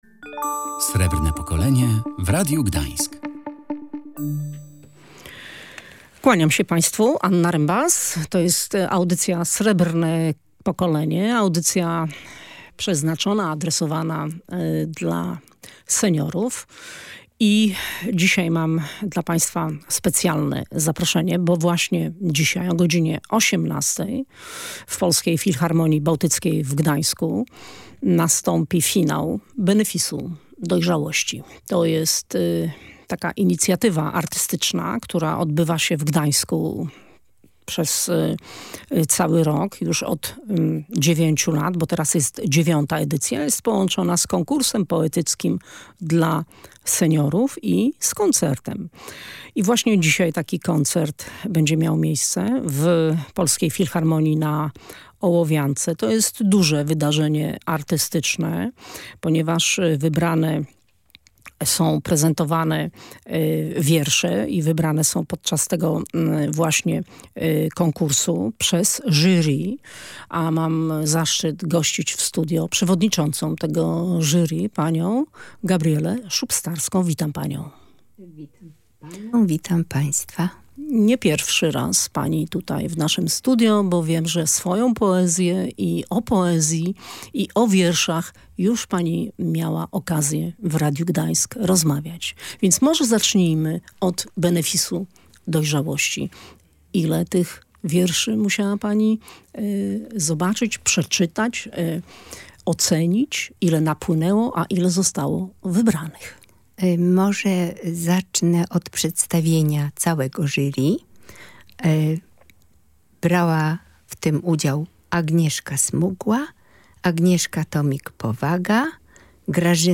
Benefis Dojrzałości, czyli twórczość poetycka gdańskich seniorów. Rozmowa